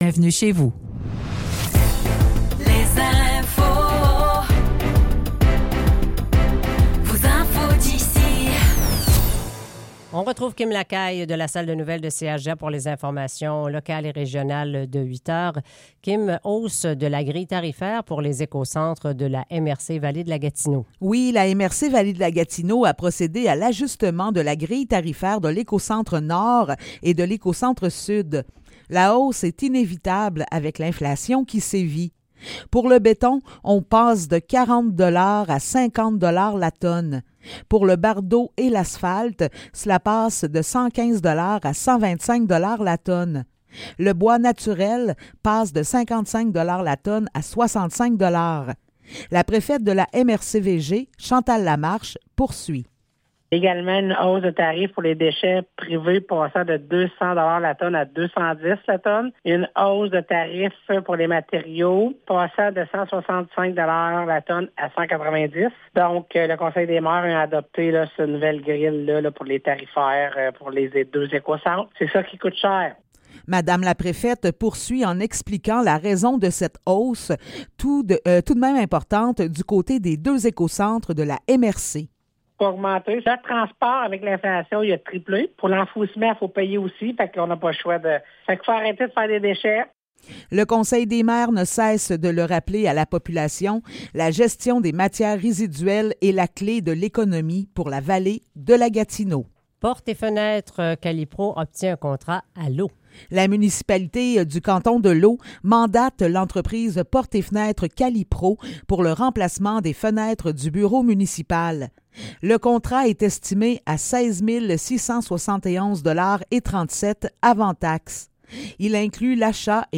Nouvelles locales - 14 décembre 2023 - 8 h